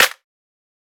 Waka SNARE ROLL PATTERN (81).wav